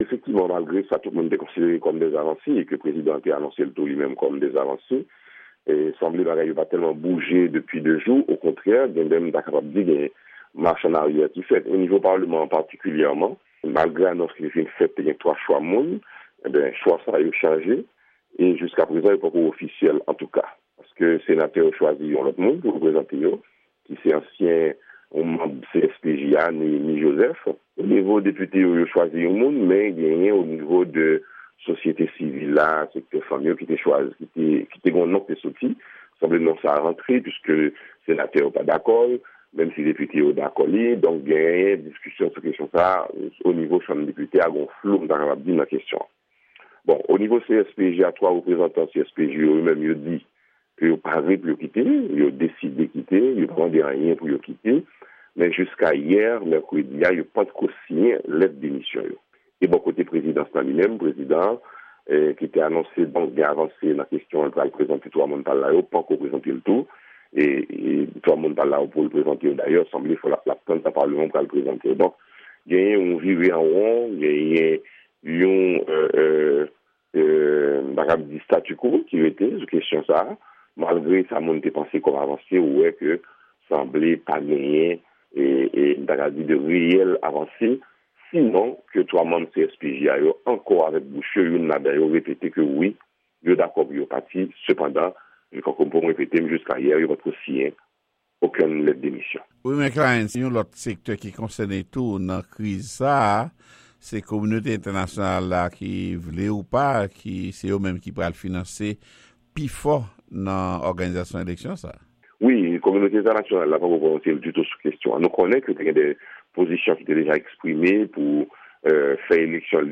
Entèvyou